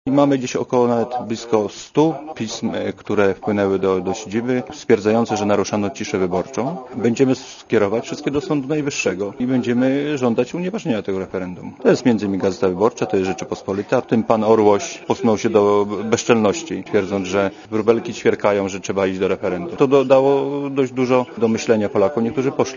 Komentarz audio (92Kb)